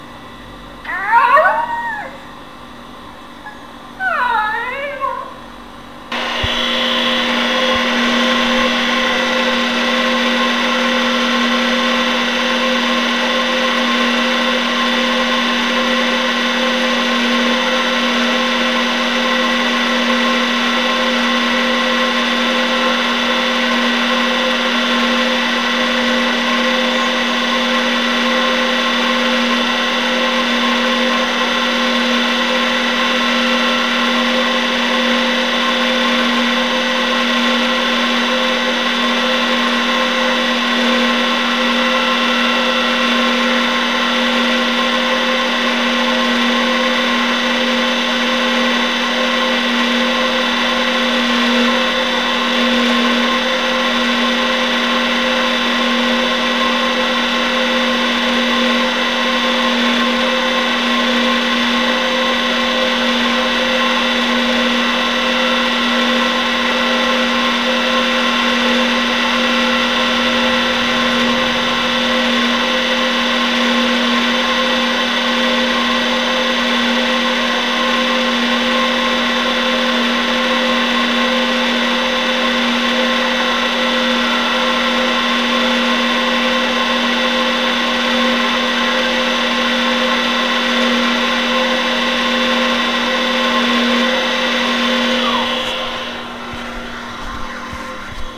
This is a water pump outside the home that is modulating to my thinking when I am close to the refrigerator.
The motor was outside the home.